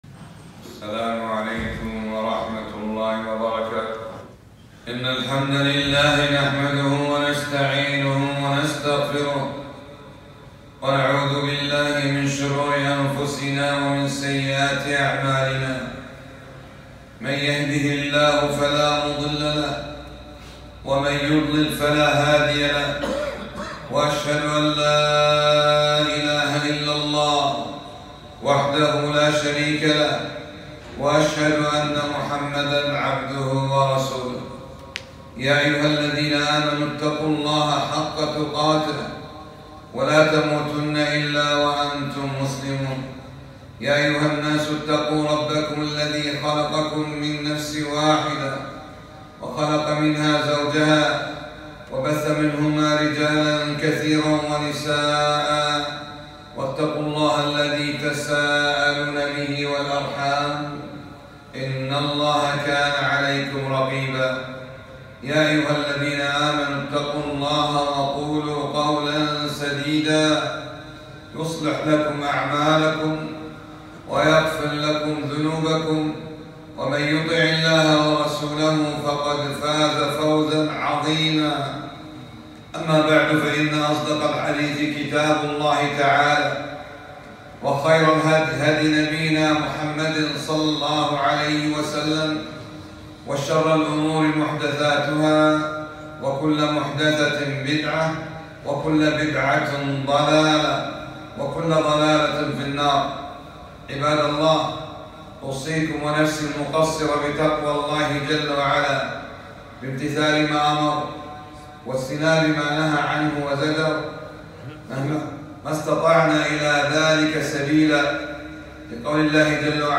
خطبة - الصبر على طاعة الله